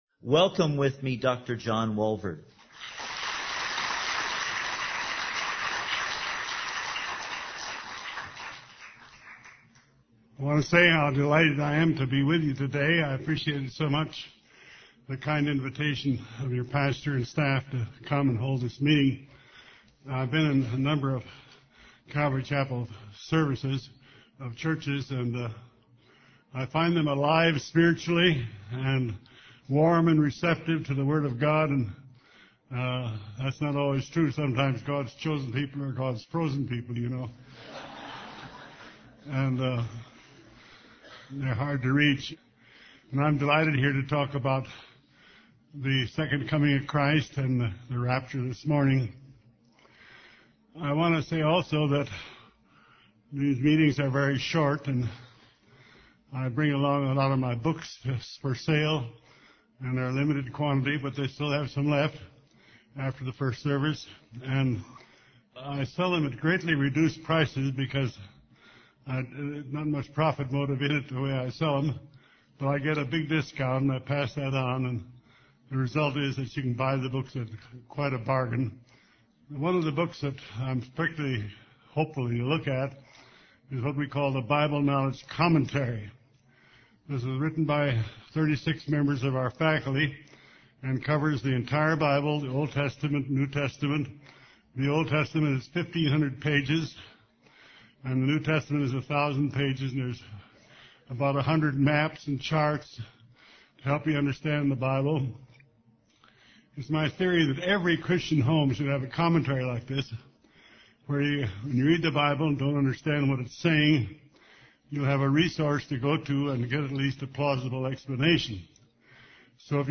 In this sermon, the preacher emphasizes that trouble and tribulation are a part of life, but the three and a half year period of the Second Coming of Christ will be a time of unparalleled trouble.